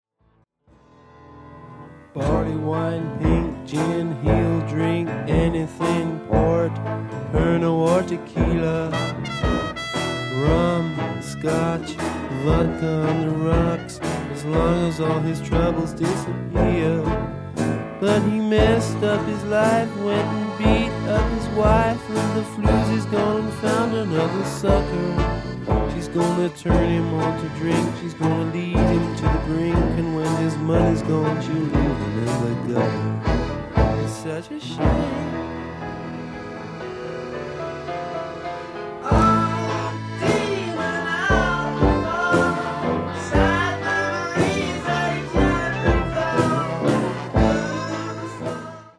abbreviated sad song